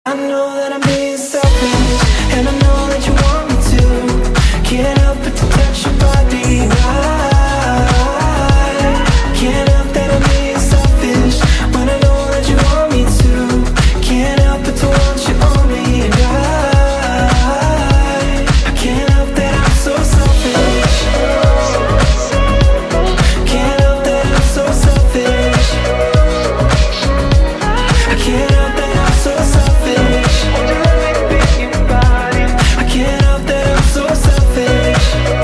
M4R铃声, MP3铃声, 欧美歌曲 58 首发日期：2018-05-13 14:54 星期日